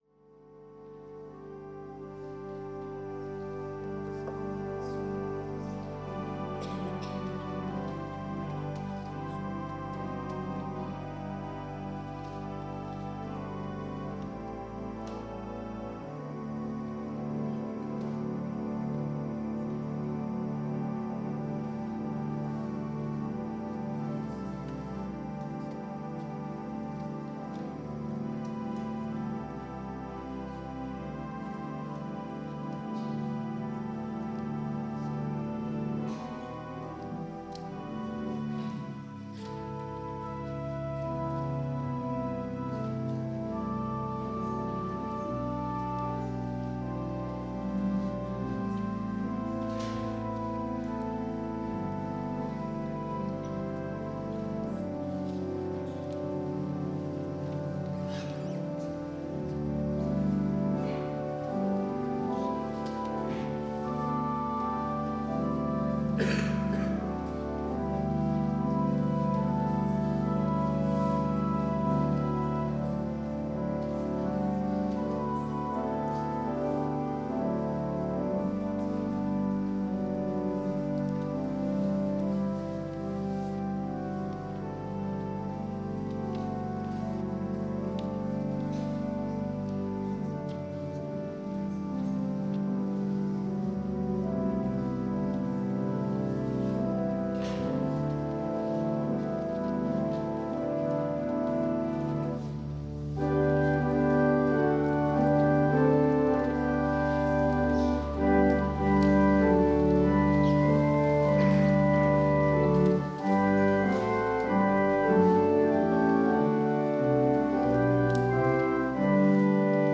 Worship and Sermon audio podcasts
WORSHIP - 11:00 p.m. Christmas Eve